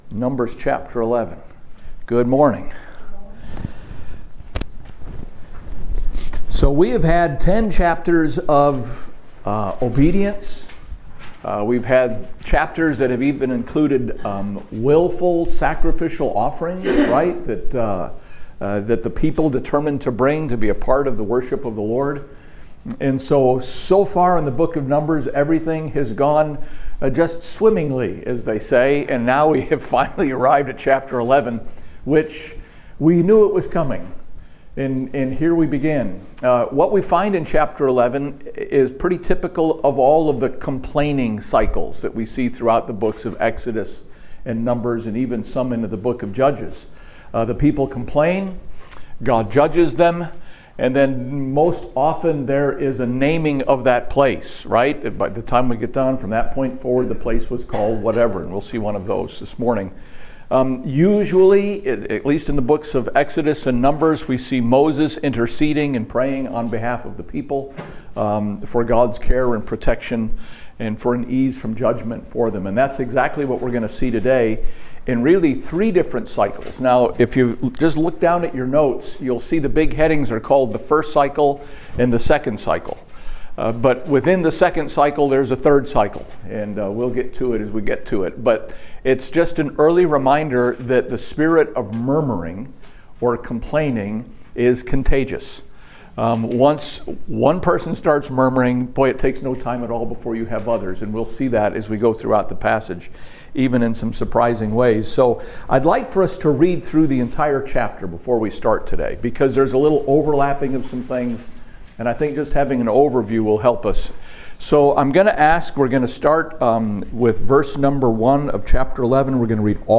Thursday Bible Study. Numbers 11 contains the first of many complaints that the Children of Israel will make during their years in the desert. They question the goodness of God.